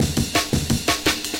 The fundamental technique in manipulating drum breaks like the Amen is to rearrange the slices to get new patterns from the drum loop – below are a series of screenshots with audio examples to show how rearranging the slices can work.